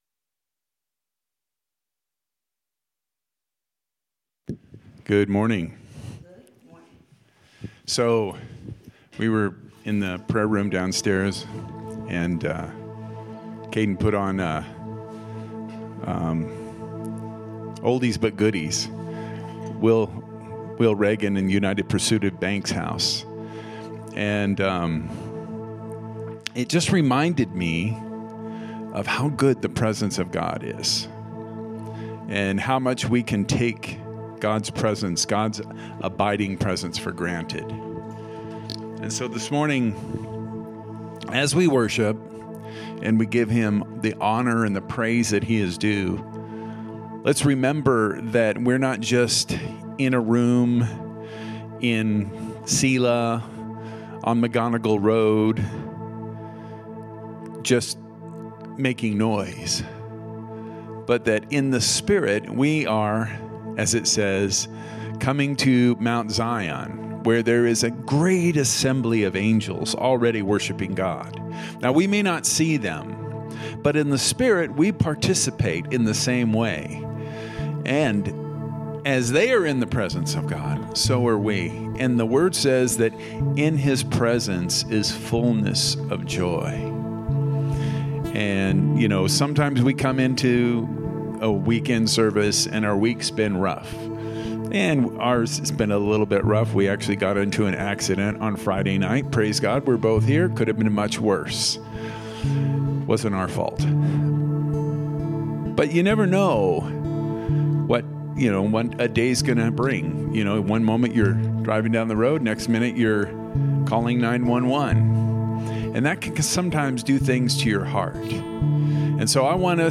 From Series: "Sermon"